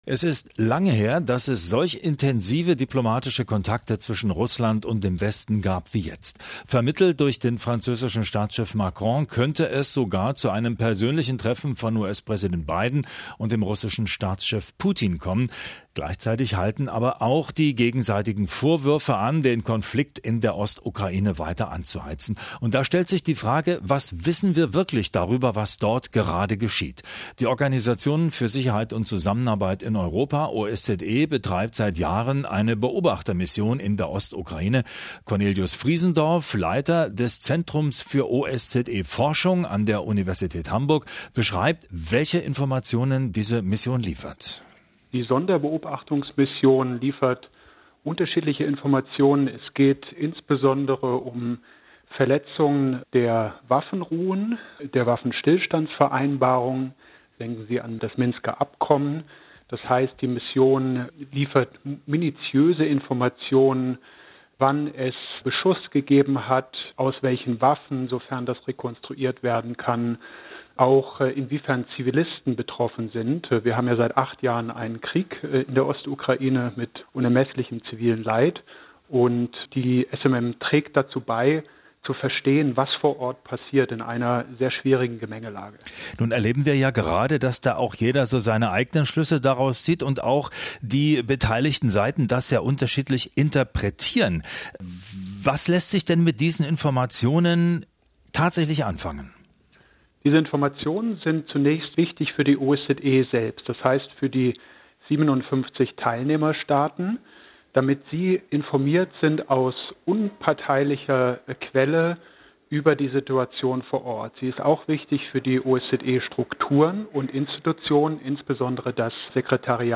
Was leistet die OSZE-Sonderbeobachtungsmission in der Ukraine? - Interview im rbb-Inforadio